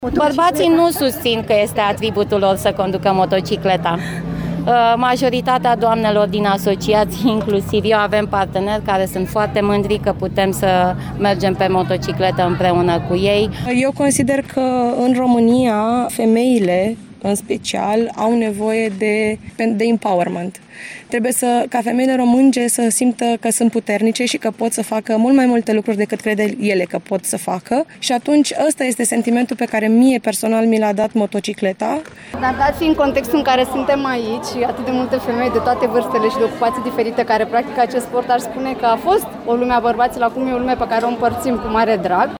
Motociclistele recomandă tuturor femeilor să încerce acest sport: